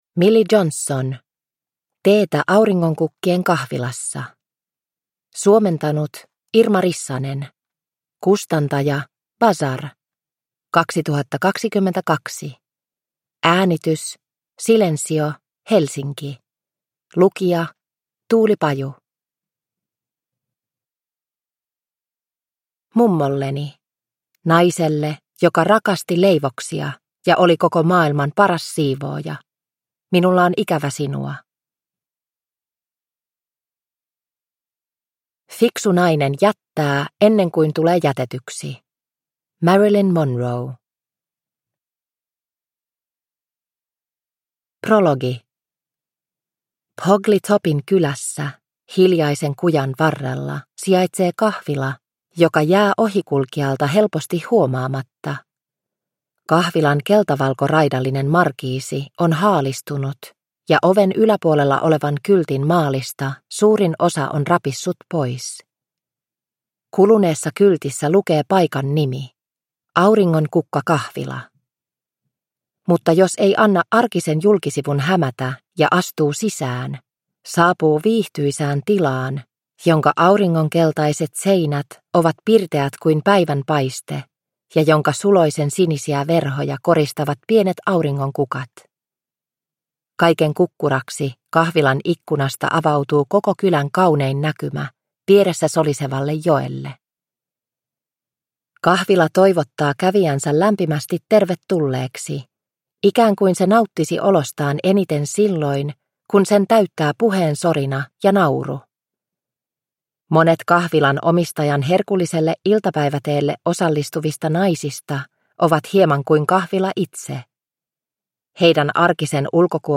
Teetä auringonkukkien kahvilassa – Ljudbok – Laddas ner